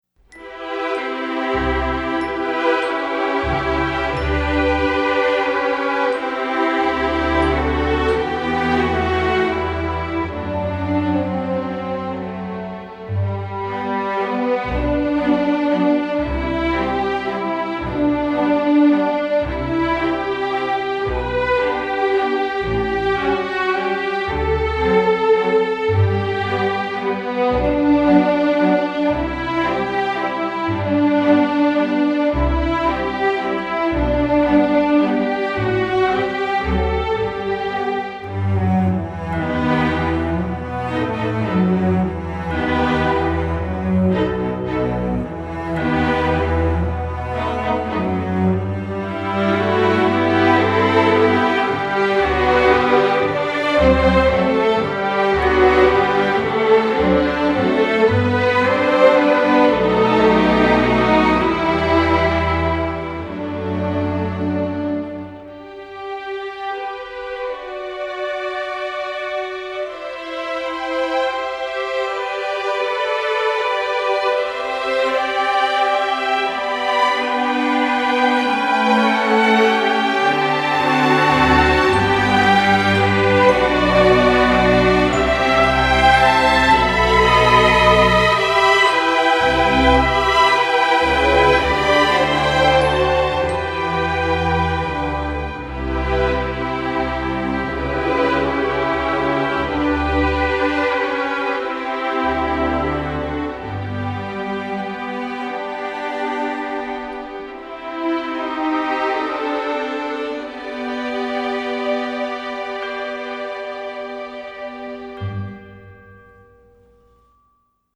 Gattung: für Streichorchester
Besetzung: Streichorchester